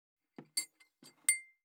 217,机に物を置く,テーブル等に物を置く,食器,グラス,コップ,工具,小物,雑貨,コトン,トン,ゴト,ポン,ガシャン,ドスン,ストン,カチ,タン,バタン,スッ,サッ,コン,
コップ効果音物を置く